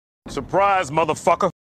surprise-motherfcker-sound-effect-perfect-cut.mp3